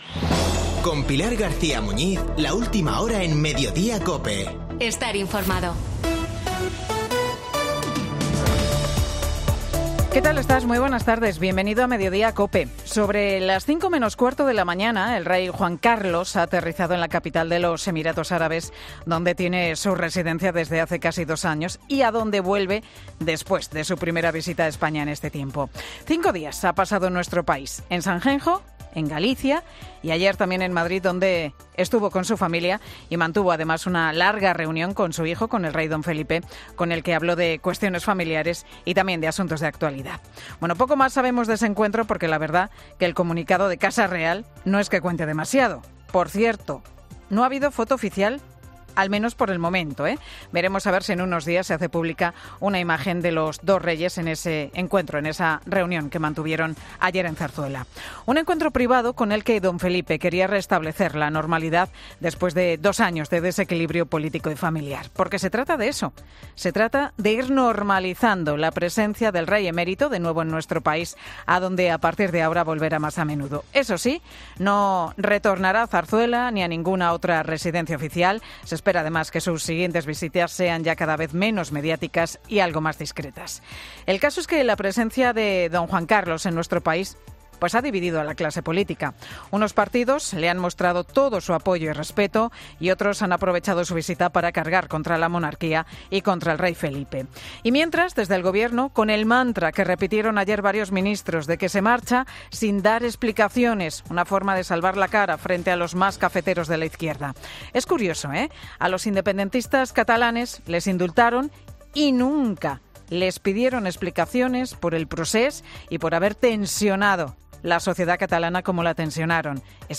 Monólogo de Pilar García Muñiz